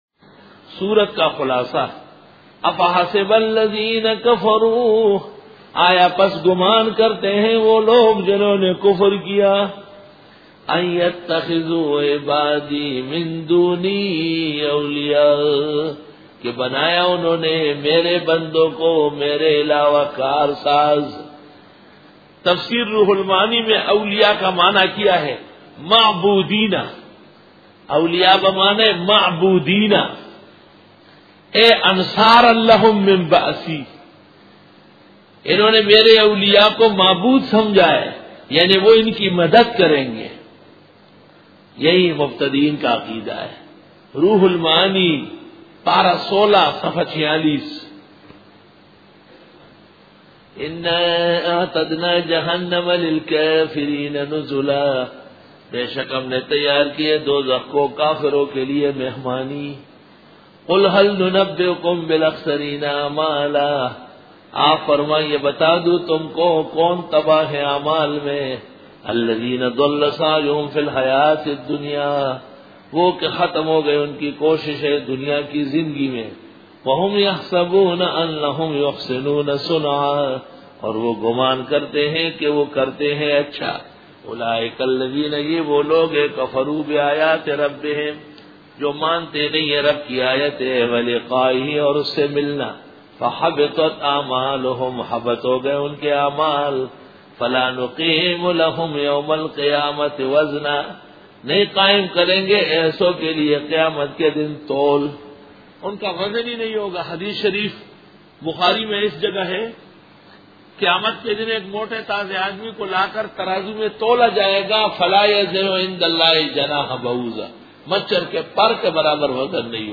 سورۃ الکھف رکوع-12 Bayan